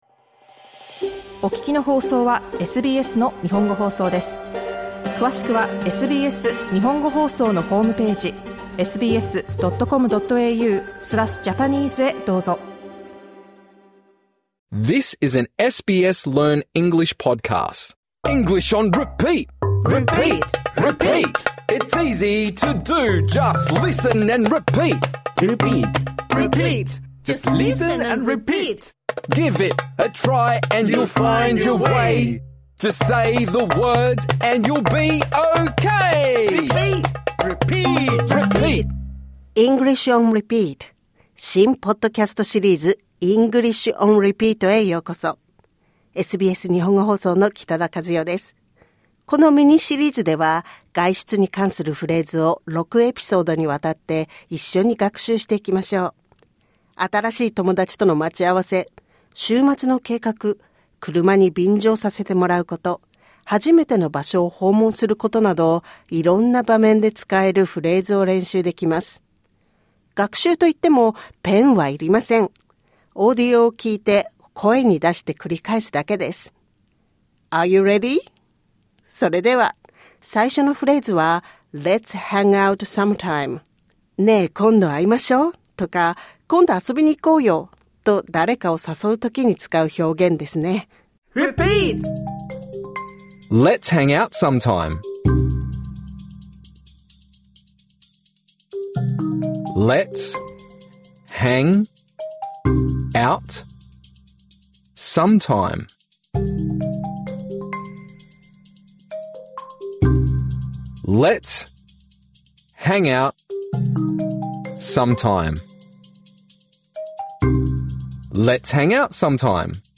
このレッスンは、初級者向けにデザインされています。 このエピソードでは、次のフレーズの練習を行います： Let’s hang out sometime.